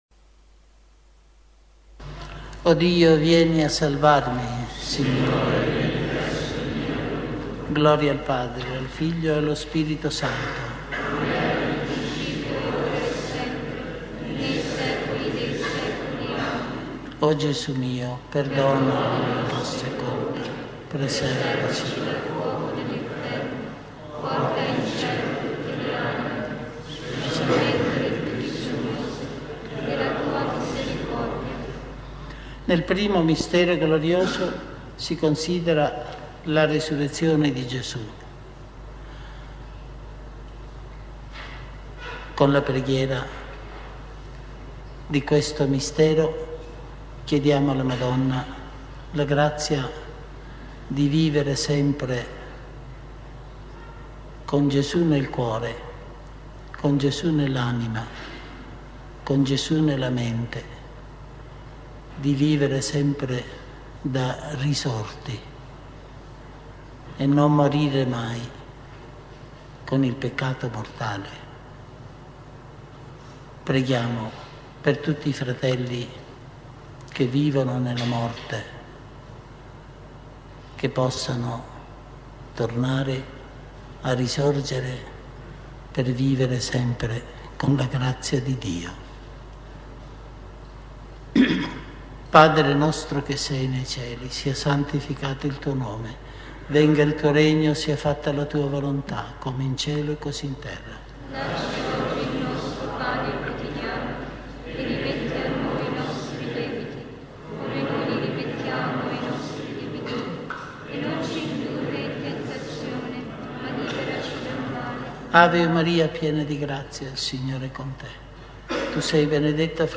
Genere: Rosario.